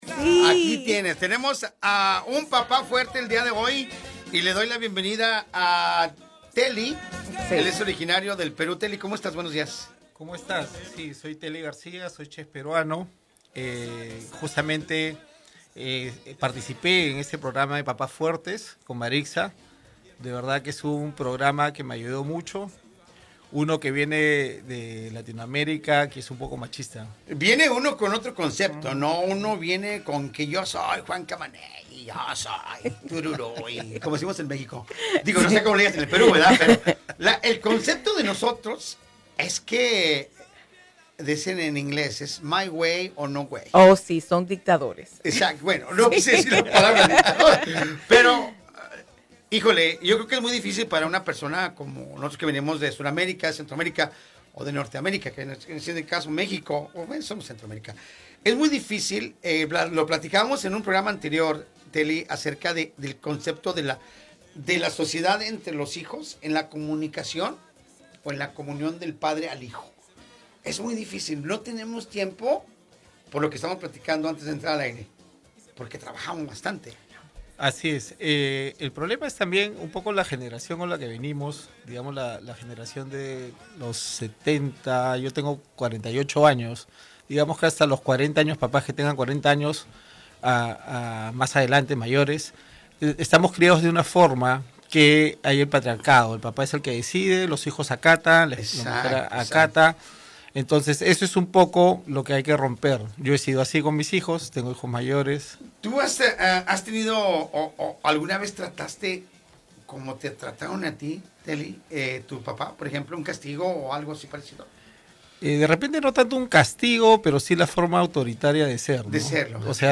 Entrevista
Escucha la voz de un papá testigo de las clases.